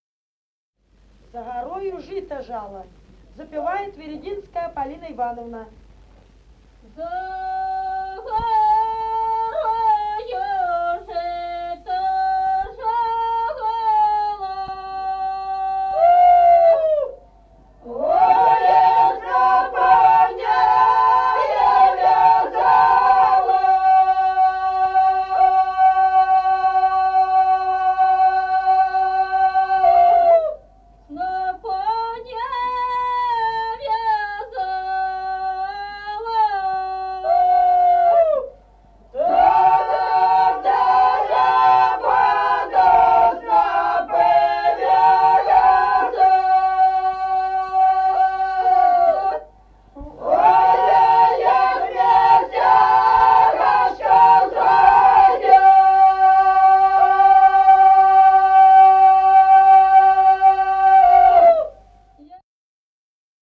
| diskname = Песни села Остроглядово.